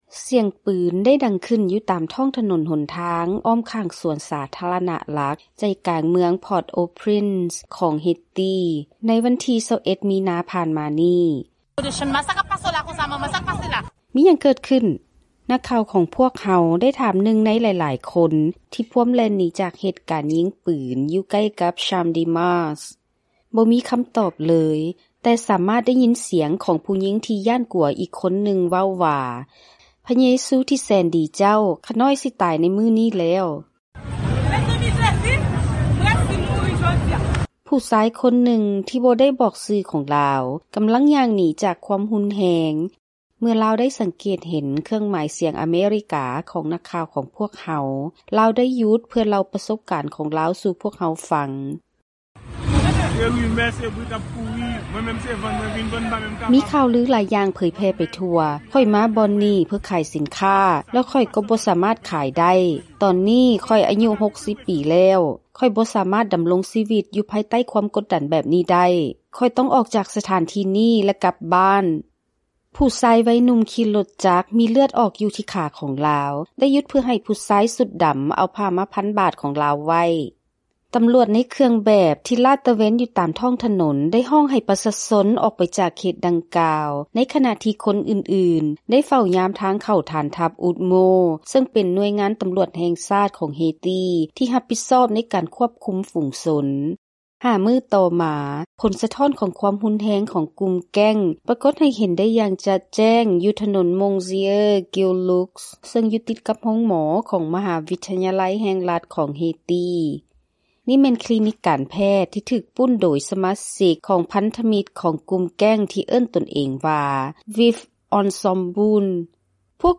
Gunfire erupted in the streets surrounding a major public park in downtown Port-au-Prince, Haiti, on March 21.
There was no answer, but another terrified woman could be heard saying, “Sweet Jesus, I am going to die today.”
Uniformed police patrolling the streets shouted for people to leave the area, as others guarded the entrance to a base of UDMO, a Haitian National Police unit responsible for crowd control.